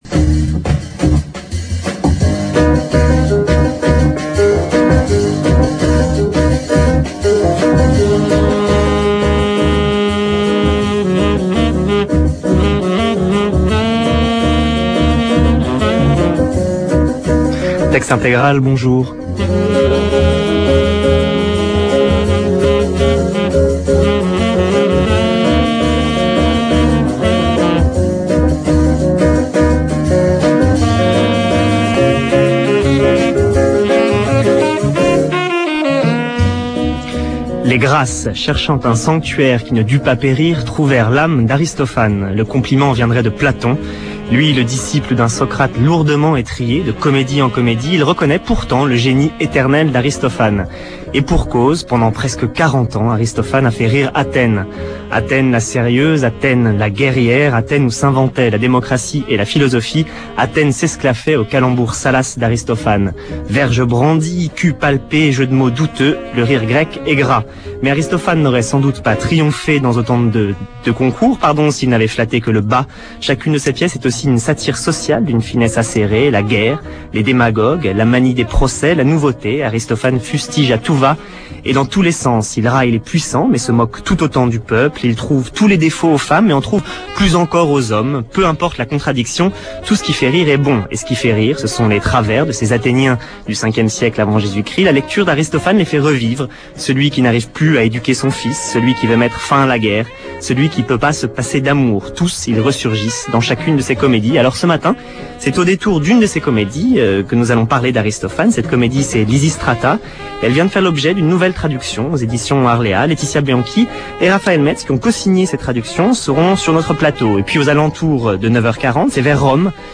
Emission